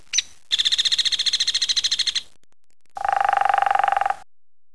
Birds:
hairywoodpecker.wav